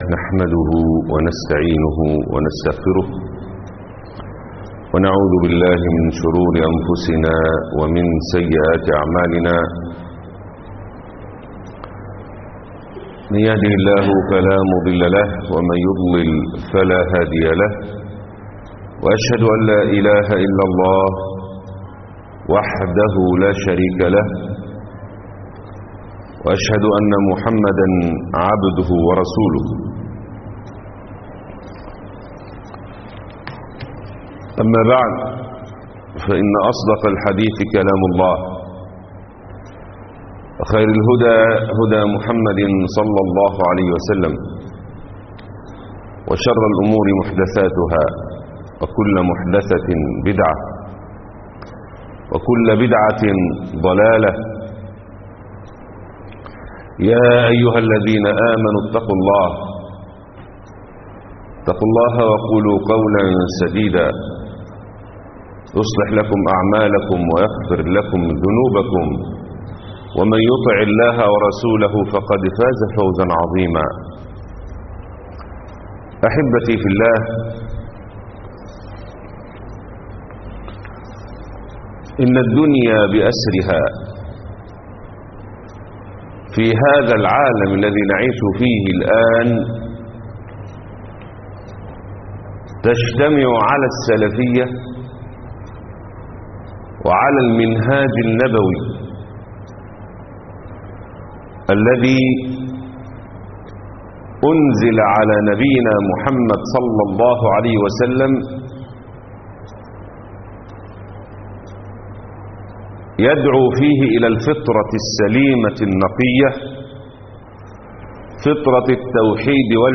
التصوف والمؤامرة على الإسلام 2 خطبة الجمعة